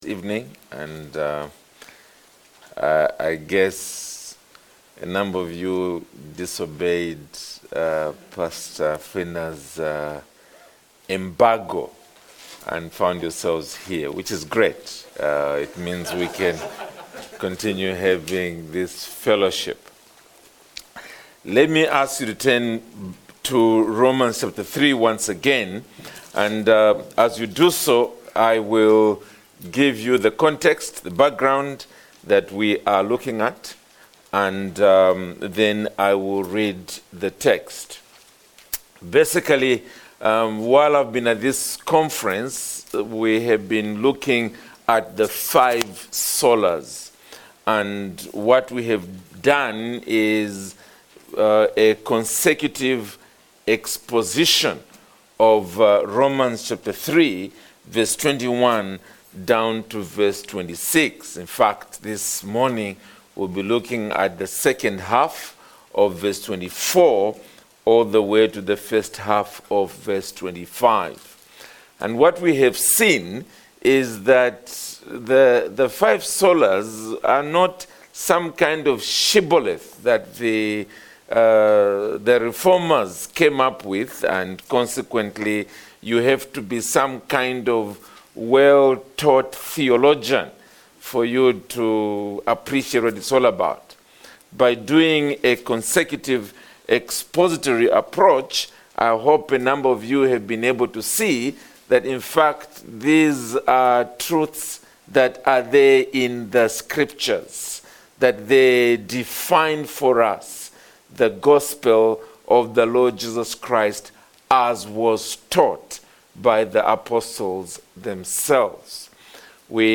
From Series: “Standalone Sermons“